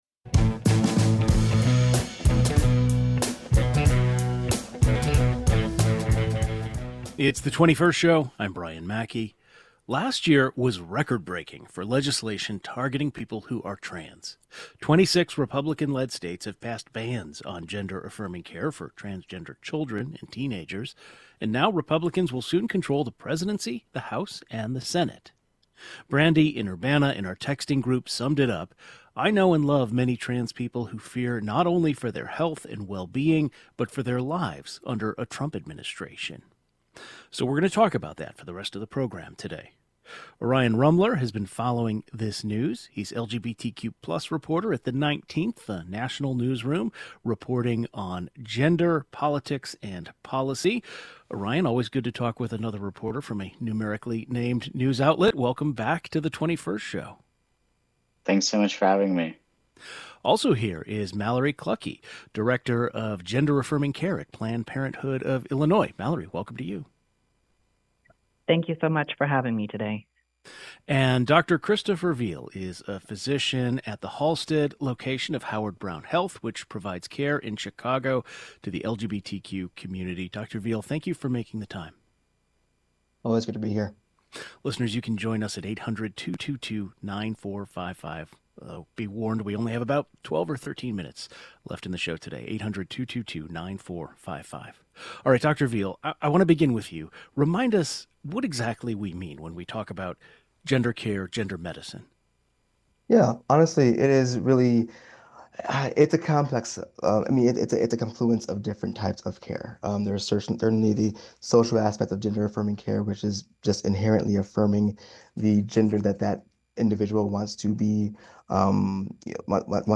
A panel of experts on the transgender community and gender-affirming weigh in.